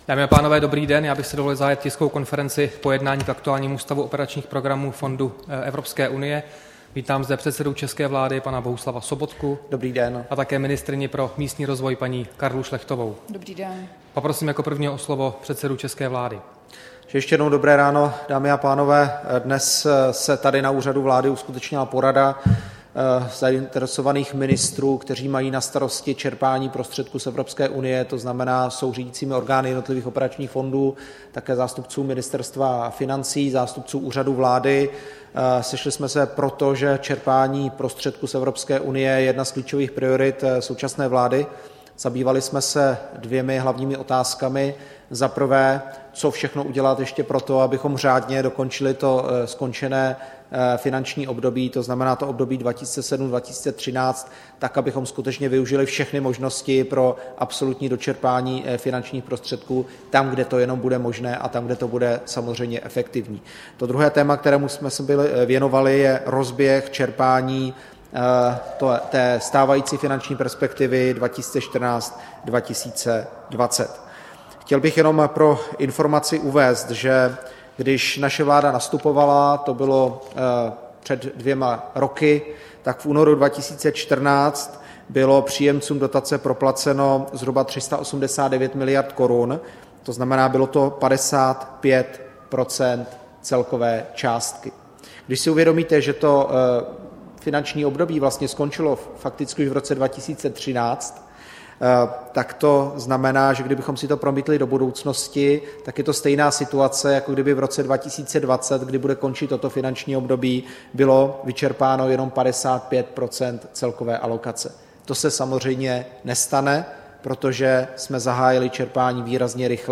Tisková konference po jednání se členy vlády o aktuálním stavu operačních programů, 28. ledna 2016